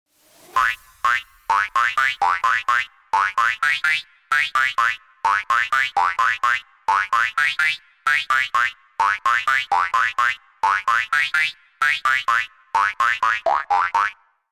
Ein Häschen hoppelt zum Song aus den 90ern